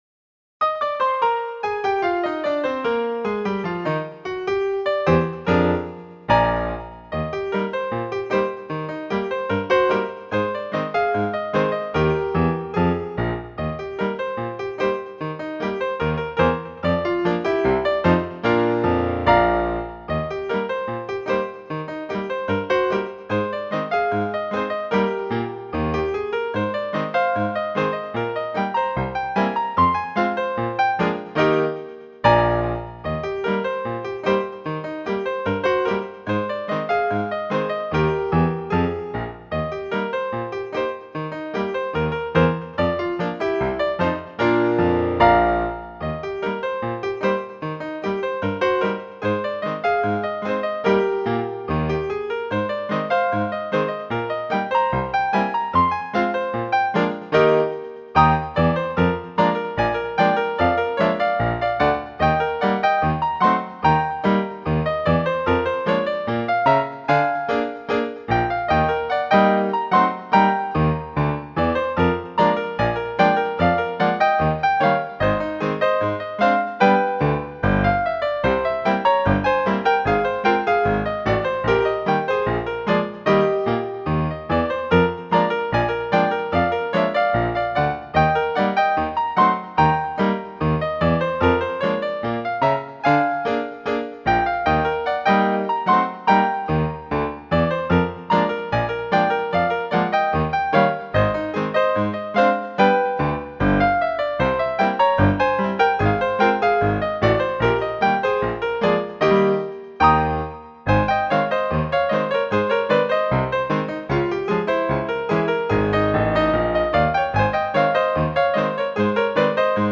Genre: Ragtime